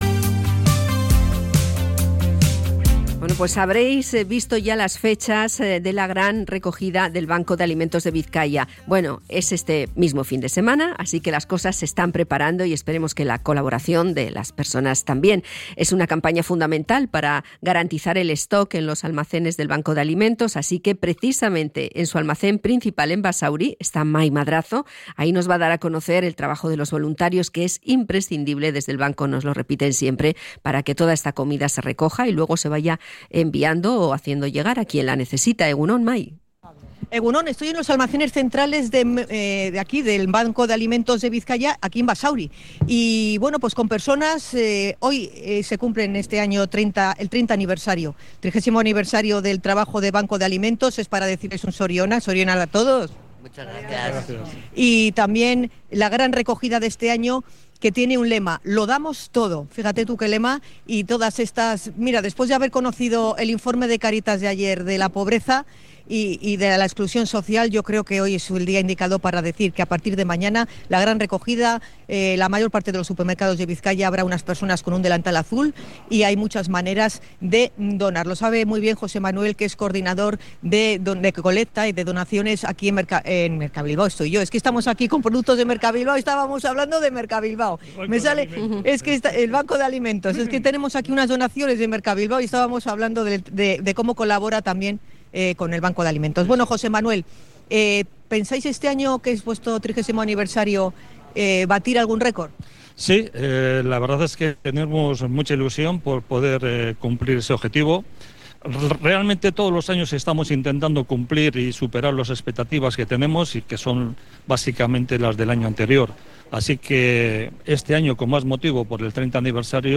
Hablamos con los voluntarios del Banco de Alimentos en sus instalaciones